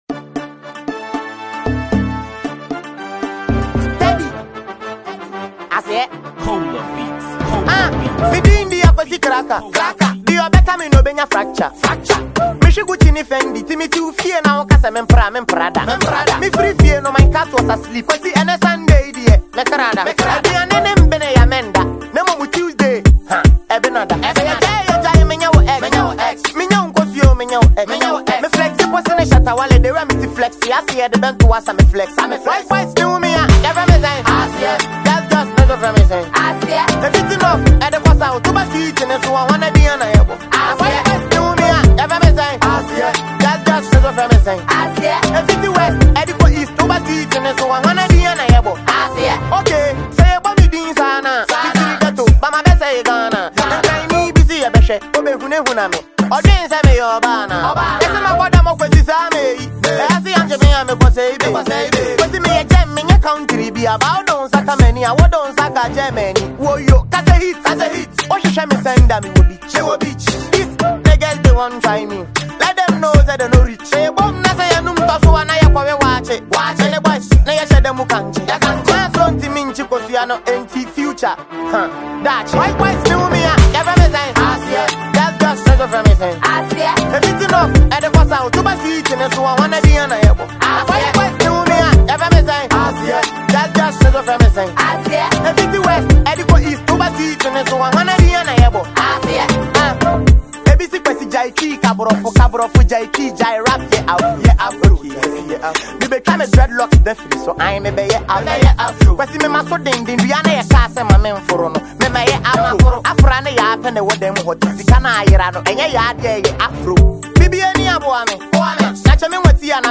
Fast-rising Ghanaian rapper